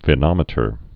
(vĭ-nŏmĭ-tər, vī-)